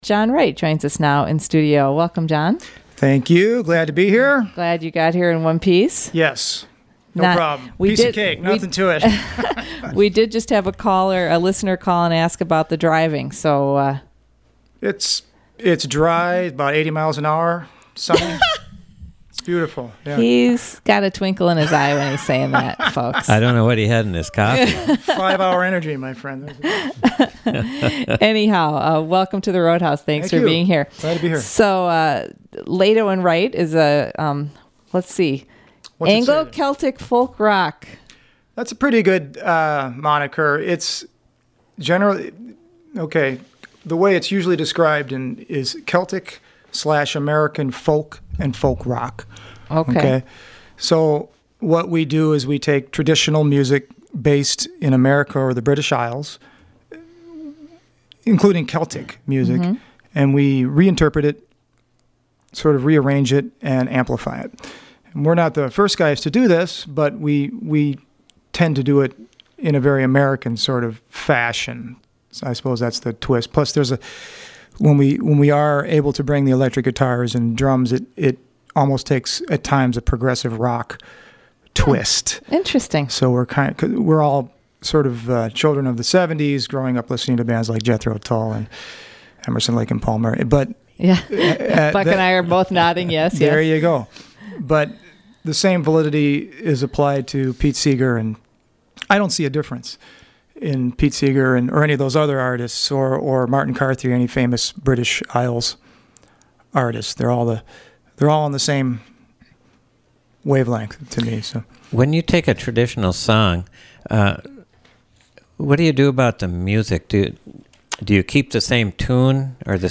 Celtic-folk-Americana
In any case, he plays some gorgeous guitar.
Live Music Archive